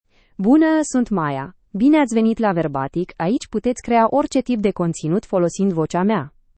Maya — Female Romanian (Romania) AI Voice | TTS, Voice Cloning & Video | Verbatik AI
MayaFemale Romanian AI voice
Voice sample
Listen to Maya's female Romanian voice.
Female
Maya delivers clear pronunciation with authentic Romania Romanian intonation, making your content sound professionally produced.